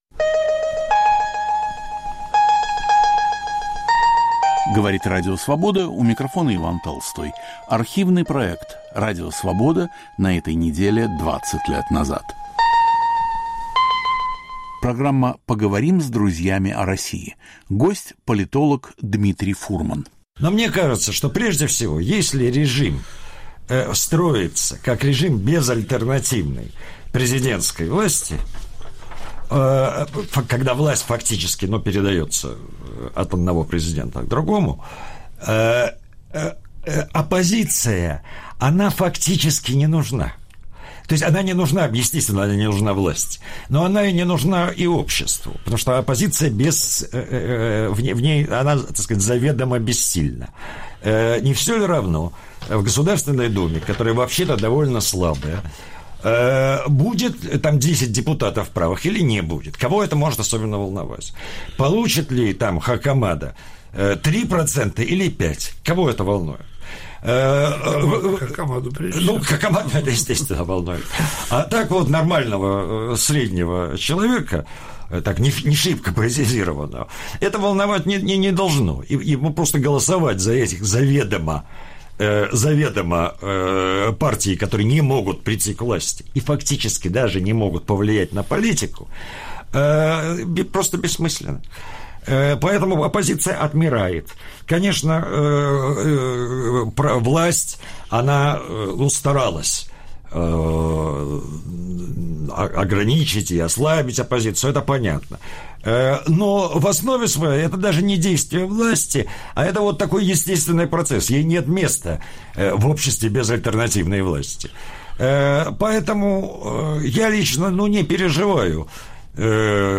В студии Радио Свобода главный научный сотрудник Института Европы РАН. Автор и ведущий передачи Егор Яковлев.